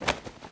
1 channel
Goliath_tackle.mp3